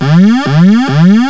stall.wav